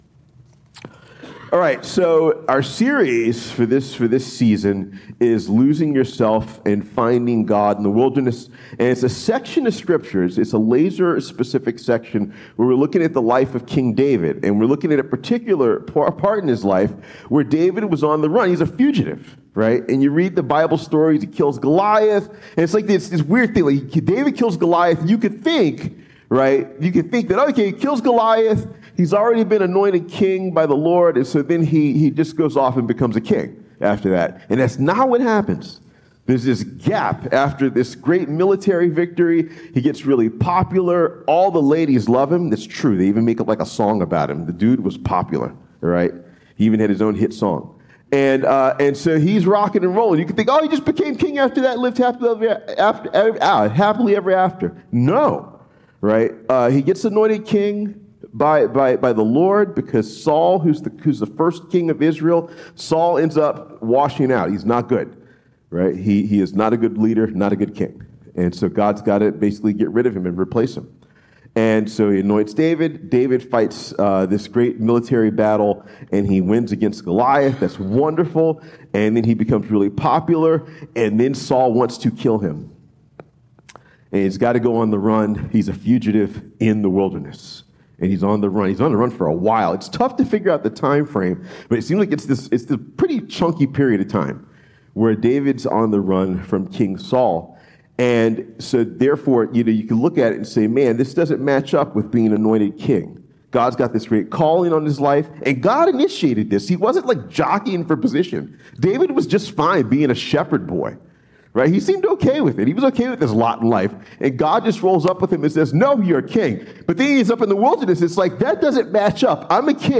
Sermons | Journey Community Church